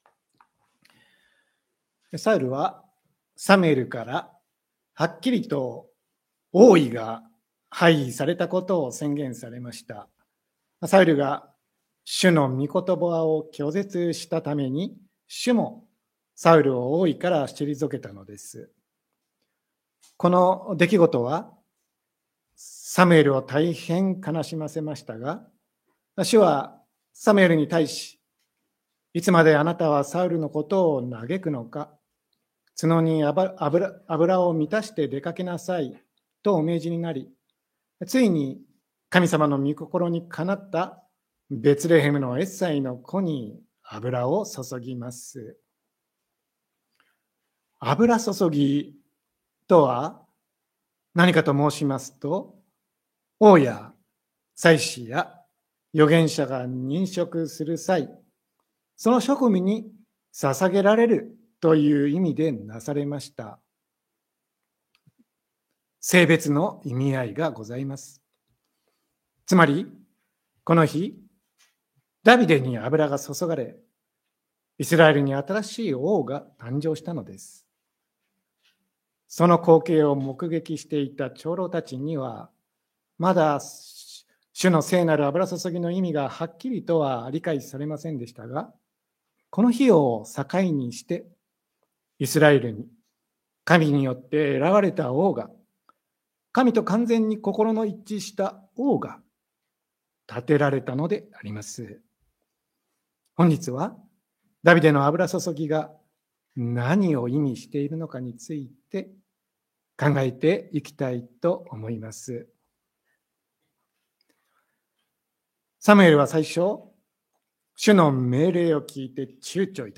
2025年11月01日朝の礼拝「ダビデへの油注ぎ 다윗의 기름 부음」せんげん台教会
千間台教会。説教アーカイブ。